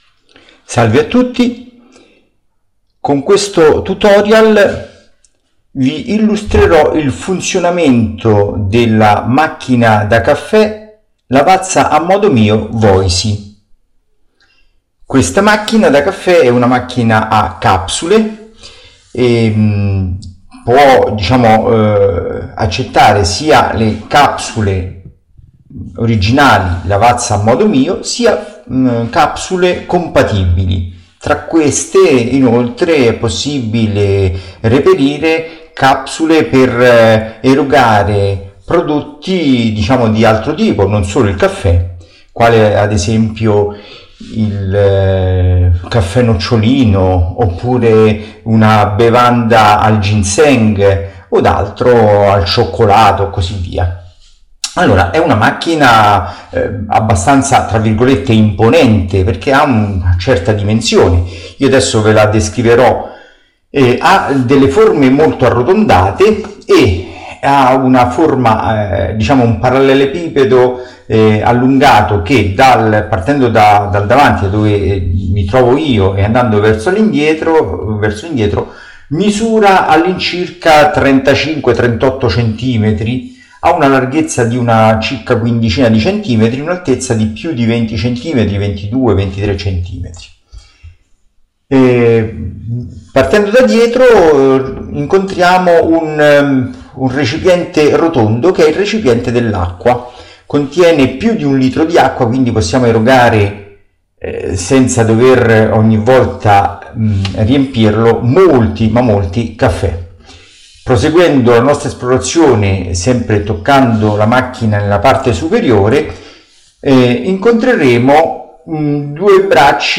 Tutorial audio sulla Lavazza Voicy Torna su Le tastiere numeriche hardware Per chi non ci vede le tastiere numeriche di tipo touch, se non vocalizzate, sono inaccessibili.